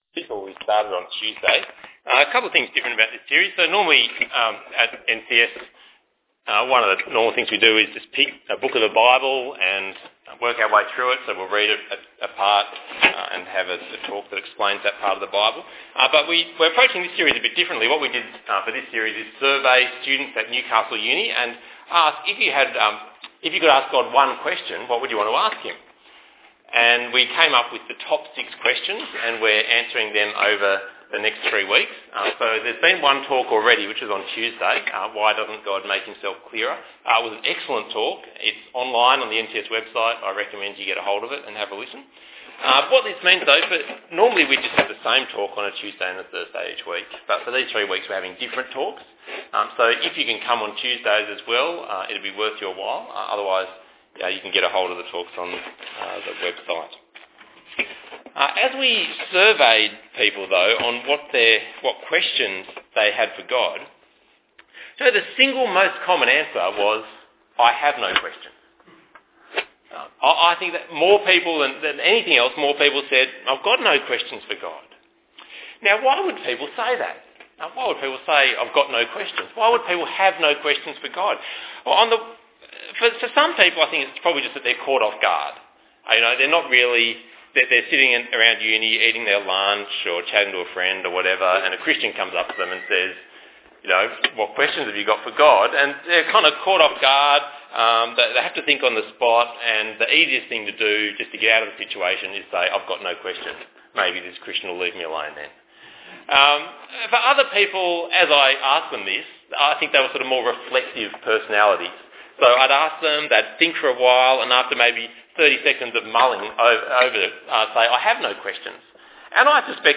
Ecclesiastes 2:1-11 Talk Type: Bible Talk Topics: apathy , life , meaning , questions « What does God think of my life?